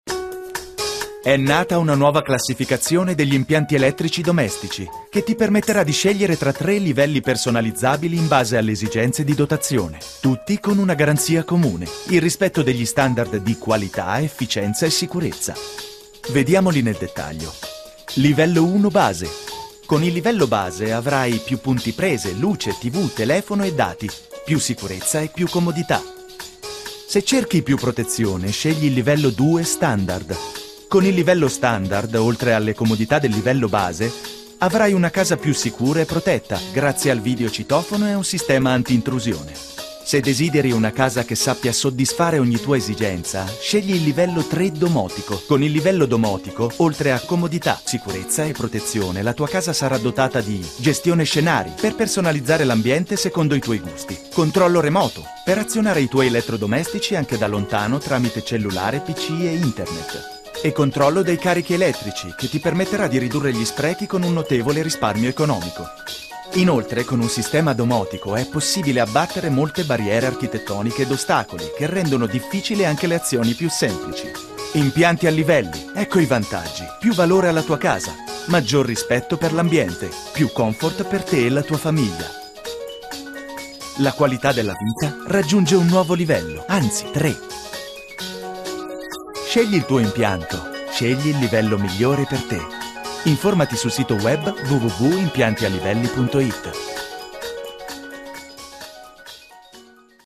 Warm, strong, clear, light, stylish...my versatile voice is avalaible for you now.
Kein Dialekt
Sprechprobe: Industrie (Muttersprache):